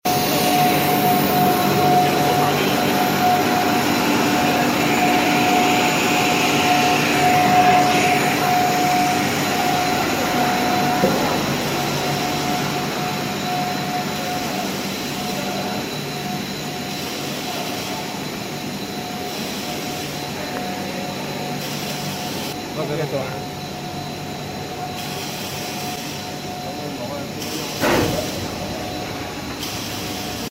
HDPE Pipe extrusion Our PPR sound effects free download